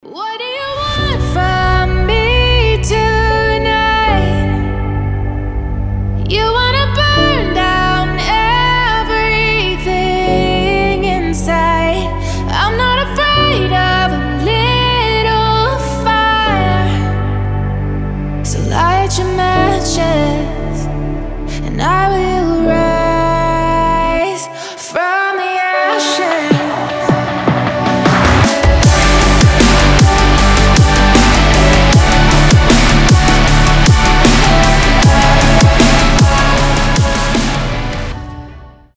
красивый женский голос
future bass
красивый вокал